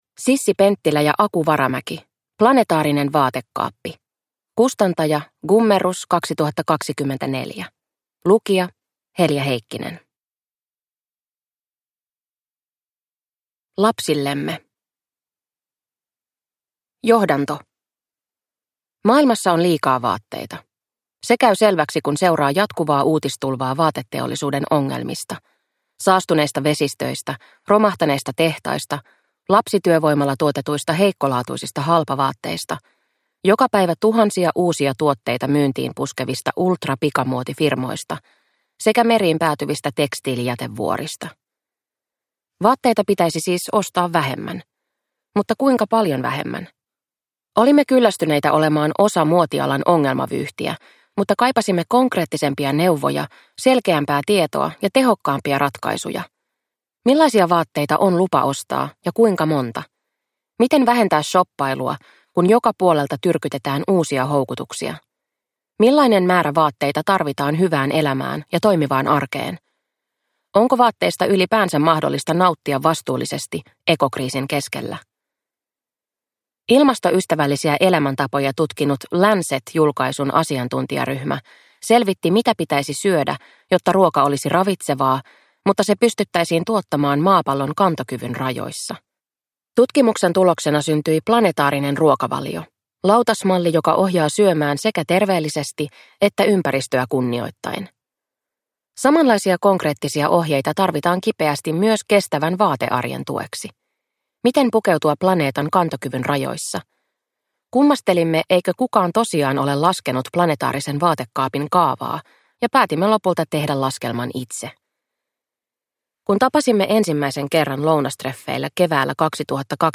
Planetaarinen vaatekaappi – Ljudbok